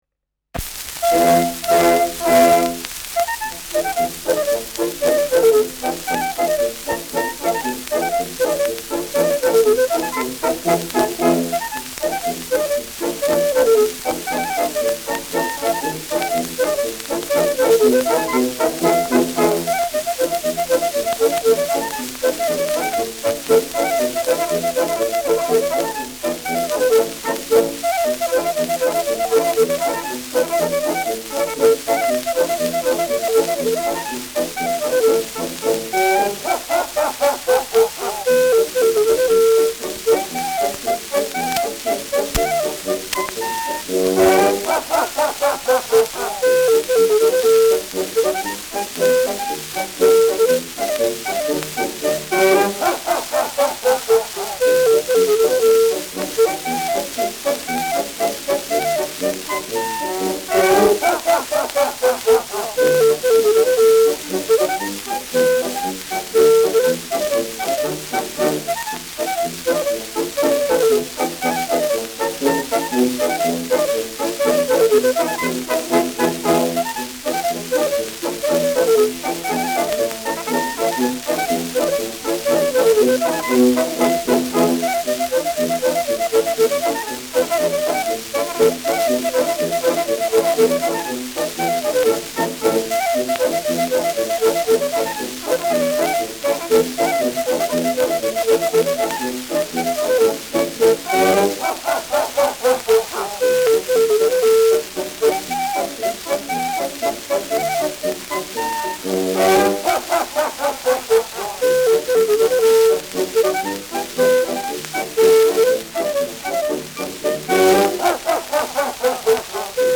Der fidele Natzl : Klarinettenschottisch [Der fidele Ignaz : Klarinettenschottisch]
Schellackplatte
präsentes Rauschen : leichtes Nadelgeräusch : vereinzeltes Knistern : vereinzeltes Knacken
Militärmusik des k.b. 14. Infanterie-Regiments, Nürnberg (Interpretation)
[Nürnberg] (Aufnahmeort)